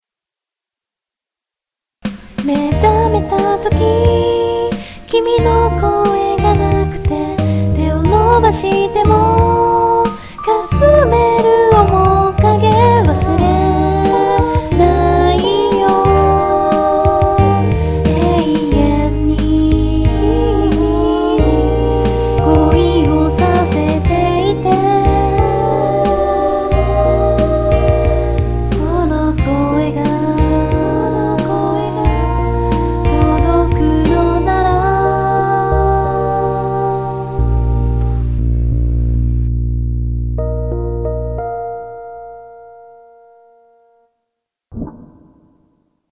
多重のコーラスにも挑戦してくださってます。かわいい歌声です。